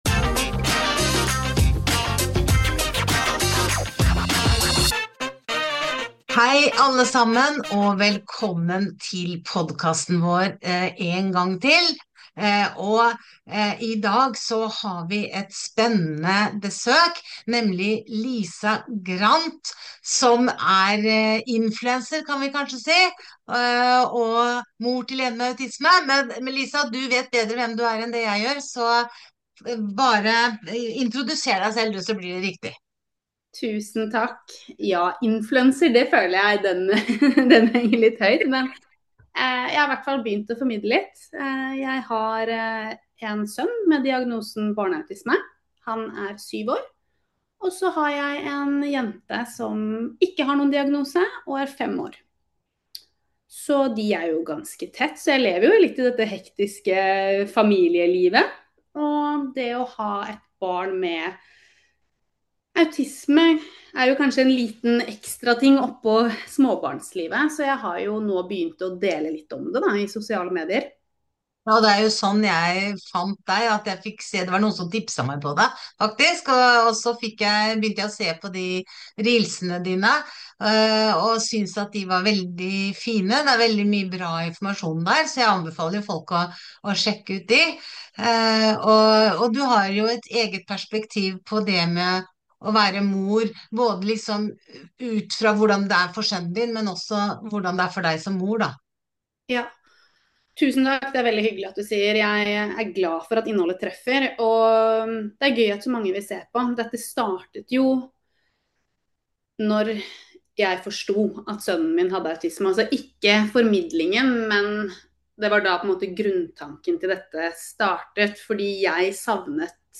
Samtale